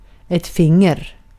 Ääntäminen
Ääntäminen Tuntematon aksentti: IPA: /ˈfɪŋɛr/ Haettu sana löytyi näillä lähdekielillä: ruotsi Käännös Substantiivit 1. sormi Artikkeli: ett .